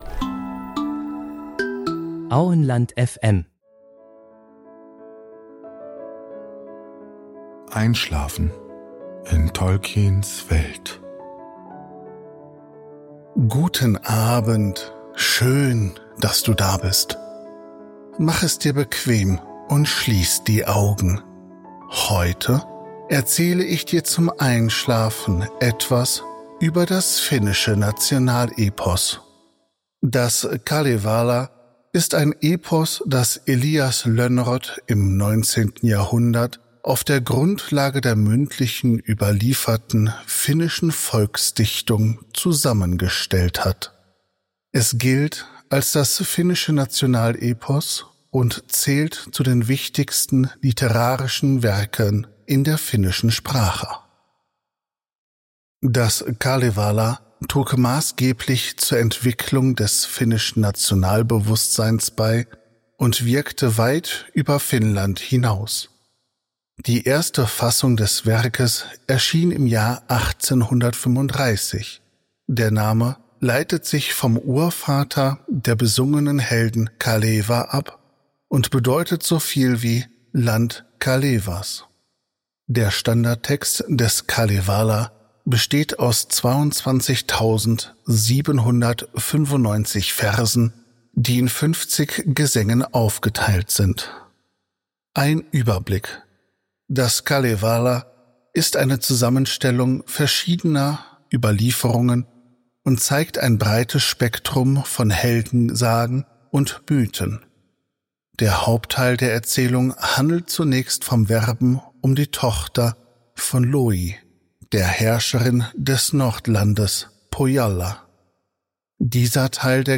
Heute erzählen wir Dir zum Einschlafen etwas aus Tolkiens Welt. Dazu lesen wir Dir ausgewählte Artikel aus der Ardapedia vor.